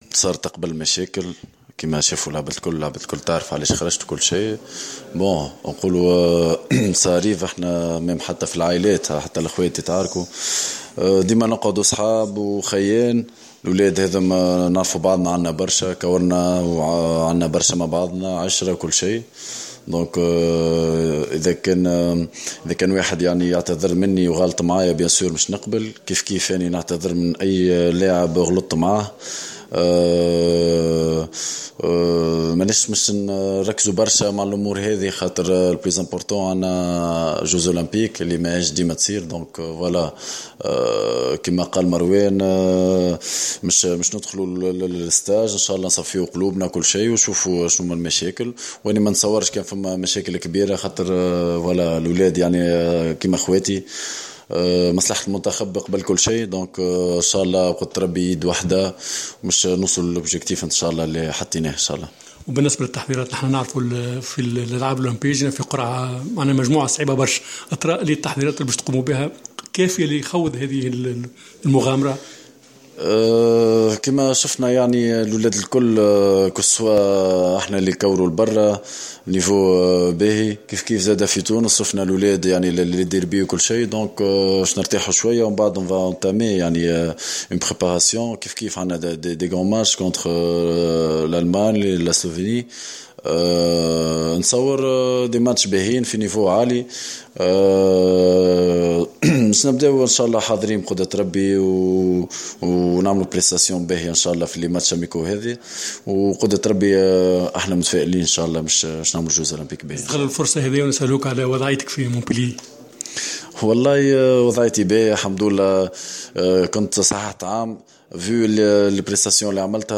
عقدت الجامعة التونسية لكرة اليد اليوم ندوة صحفية للحديث عن تحضيرات المنتخب...